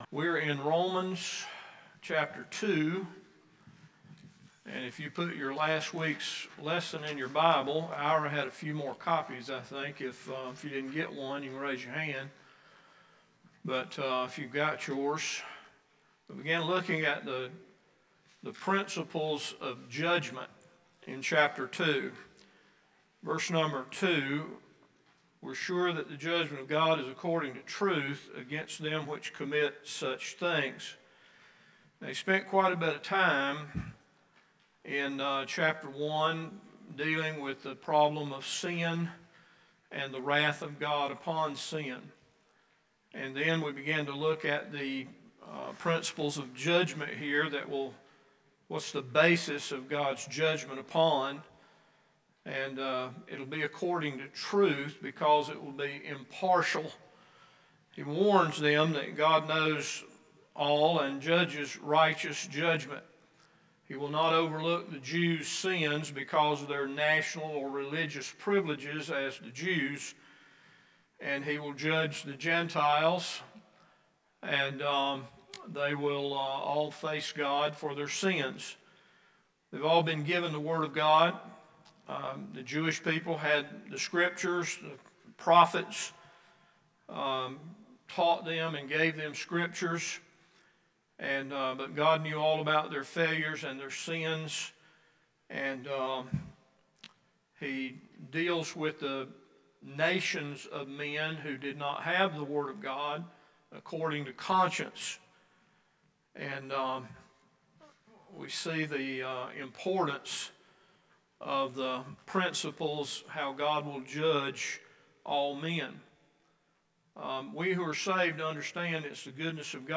Romans Bible Study 4 – Bible Baptist Church